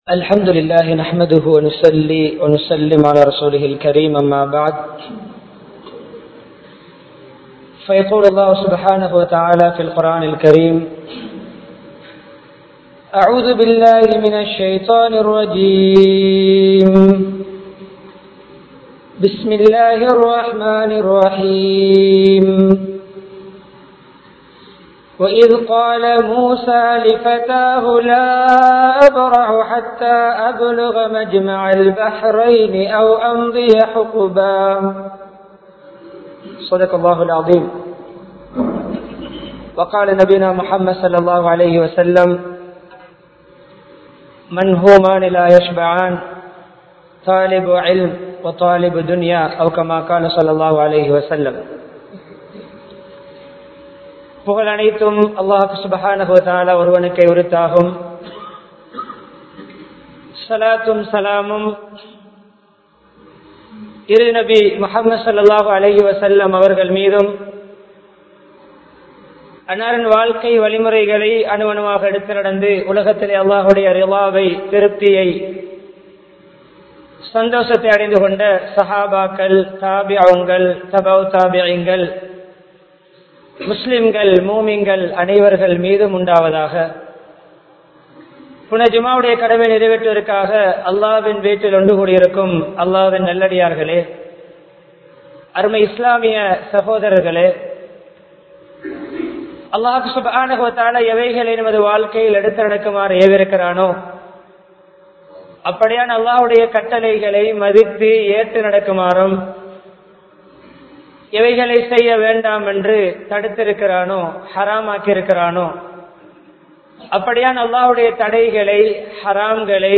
Kalvi Veelchikkaana Kaaranihal (கல்வி வீழ்ச்சிக்கான காரணிகள்) | Audio Bayans | All Ceylon Muslim Youth Community | Addalaichenai
Kandy, Galhinna, Grand Jumua Masjidh